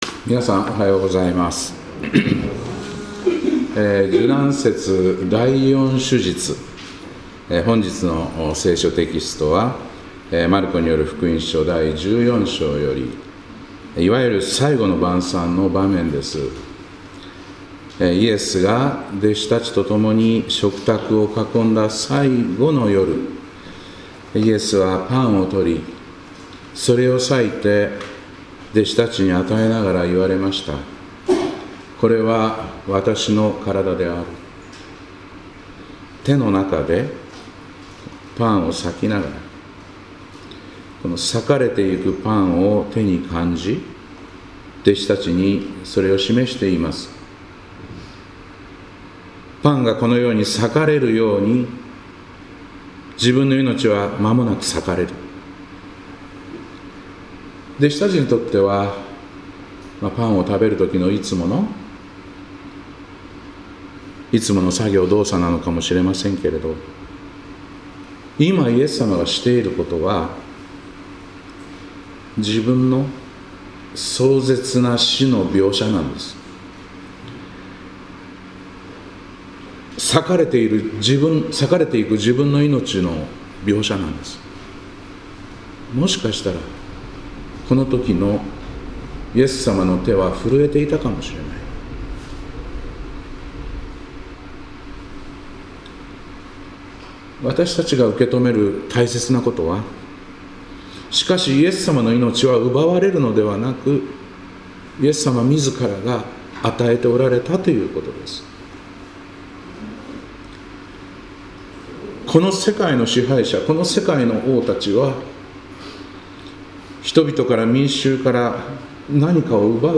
2026年3月15日礼拝「主の食卓・新たないのちの宴」